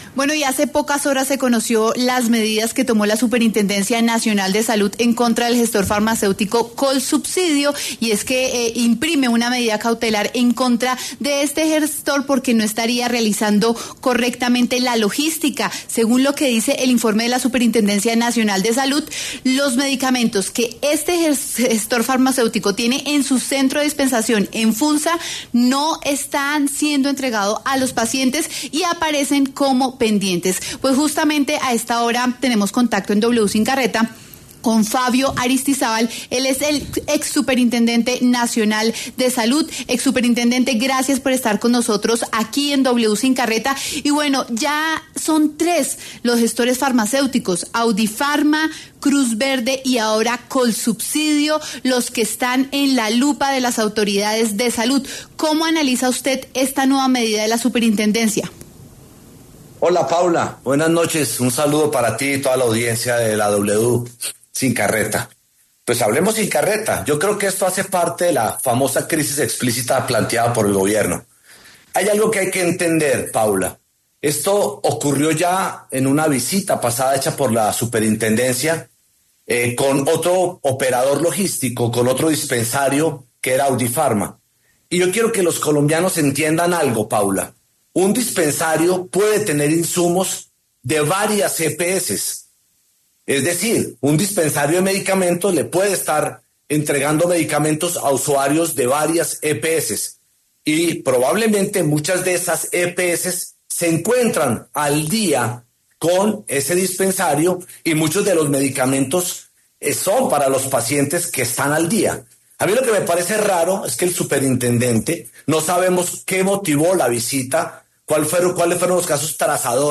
Fabio Aristizábal, exsuperintendente de Salud, pasó por los micrófonos de W Sin Carreta para conversar acerca de la medida cautelar de la Superintendencia al gestor farmacéutico Colsubsidio.